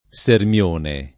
Sermione [ S erm L1 ne ]